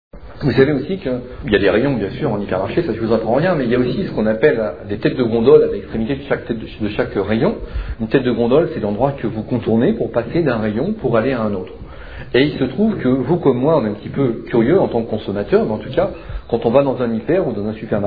Conférence tenue le 18 août 2004 à Montpellier